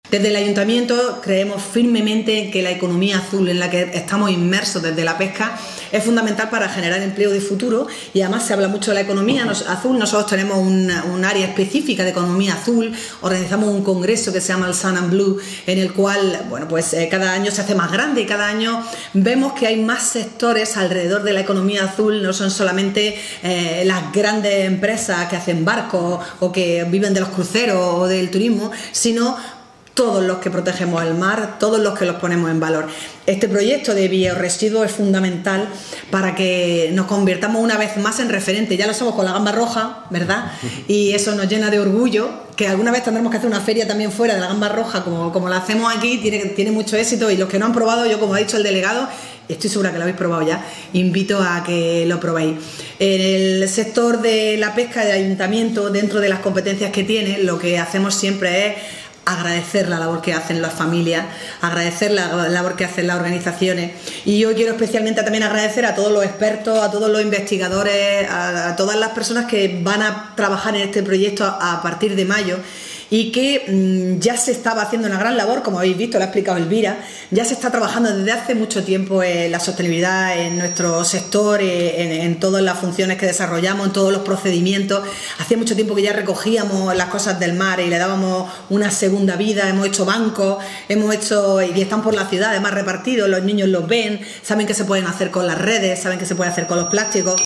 La alcaldesa de Almería, María del Mar Vázquez, ha clausurado este viernes la jornada de presentación del proyecto ‘Life Dream–Restauración de arrecifes profundos y economía circular en el Mediterráneo’ celebrada en el Puerto de Almería, donde ha destacado que iniciativas como esta “van a ayudar a que seamos una ciudad pionera en la implementación de tecnologías sostenibles”.
CORTE-ALCALDESA.mp3